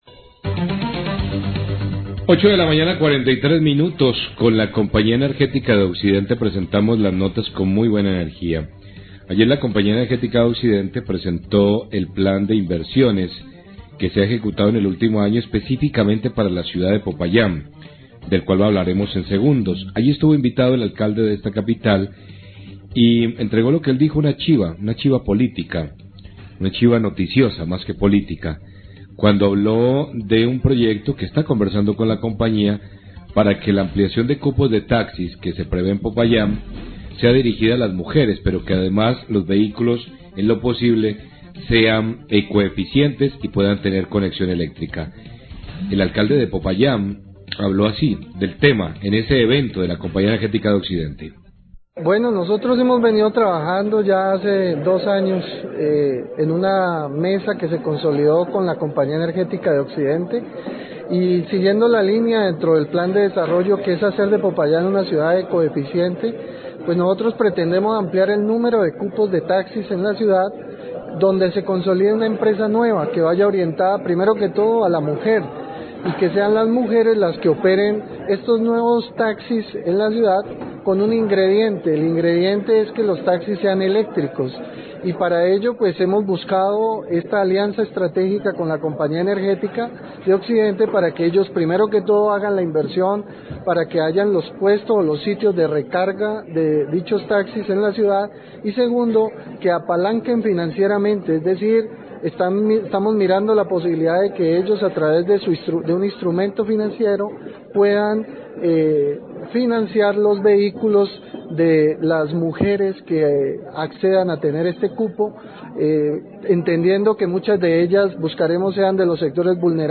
ALCALDE DE POPAYÁN HABLA DE NUEVOS CUPOS PARA TAXIS ELÉCTRICOS,